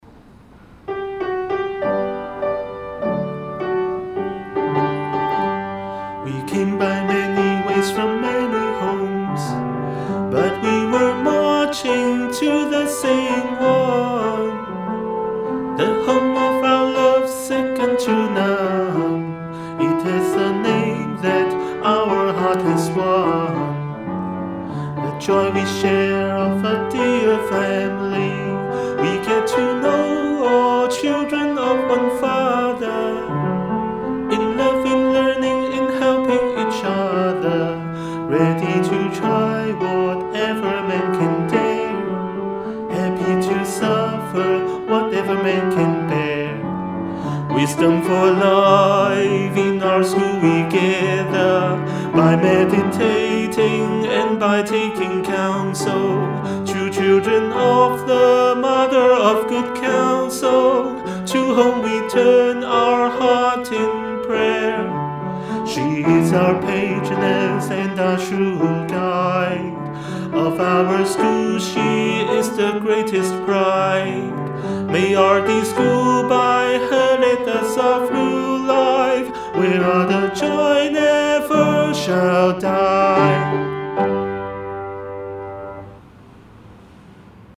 School Song
school_song.mp3